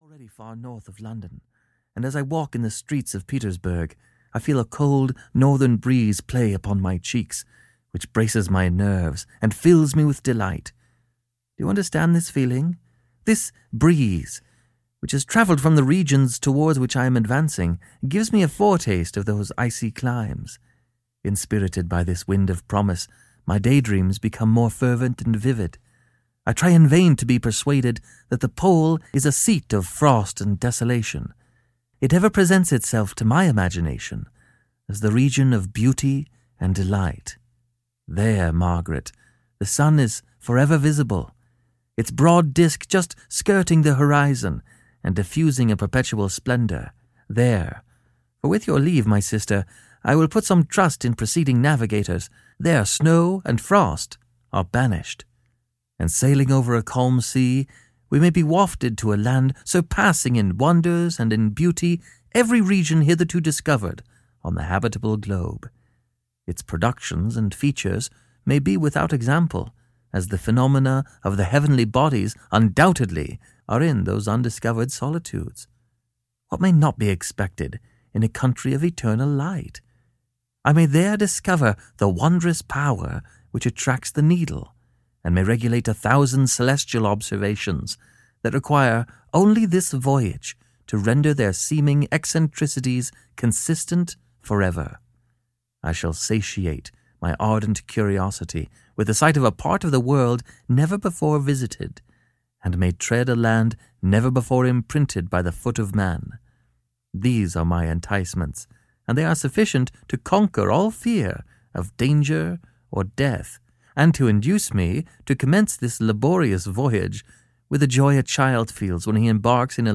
Frankenstein and Other Stories (EN) audiokniha
Ukázka z knihy